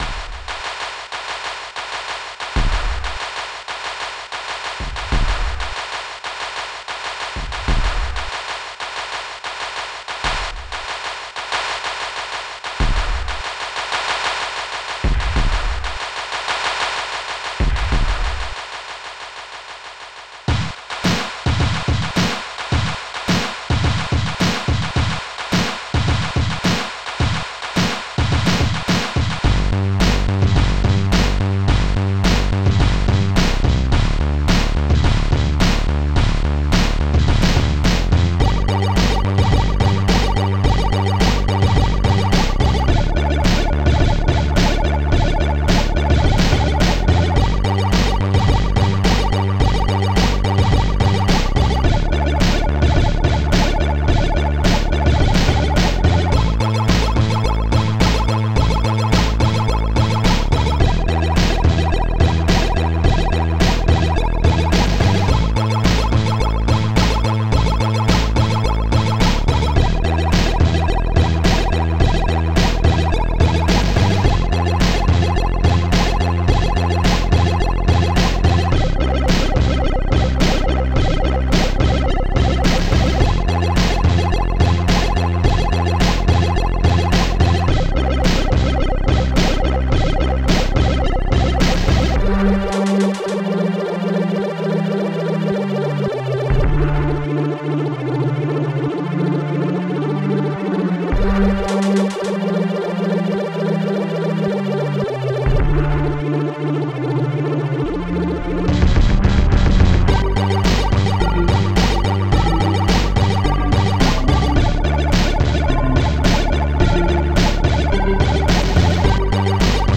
victorycla starsnare bassdrum9 snare7 polysynth peck strings2